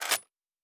Weapon 01 Foley 1.wav